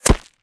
bullet_impact_dirt_02.wav